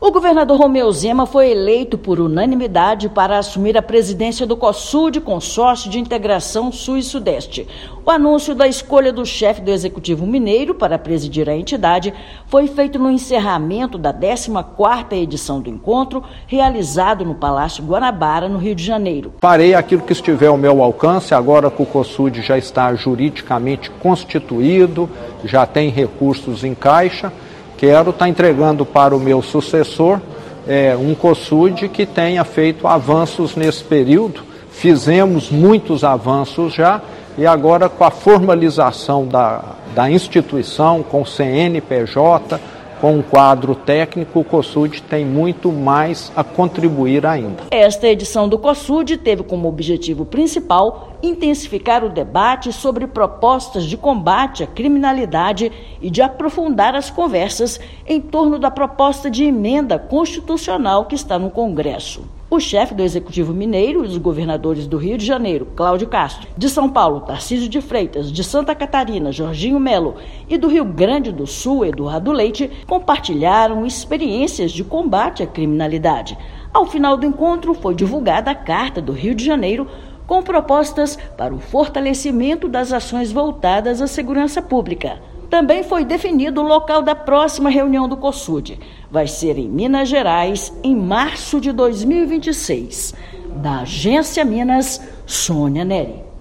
[RÁDIO] Governador de Minas assume presidência do Cosud no 14ª encontro do Consórcio
Solenidade também marcou apresentação da carta do Rio de Janeiro, com propostas para a segurança pública. Ouça matéria de rádio.